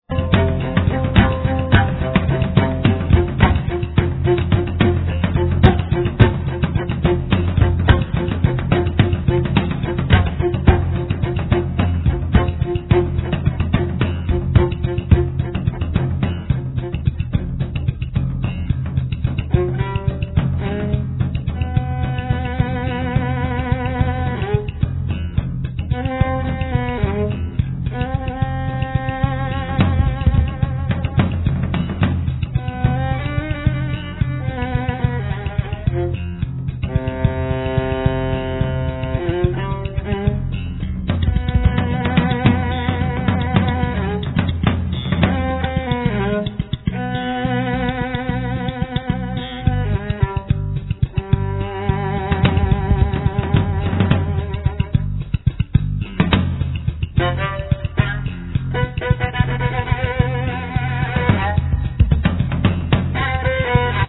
Viola, Vocals
Guitar-bass
Drums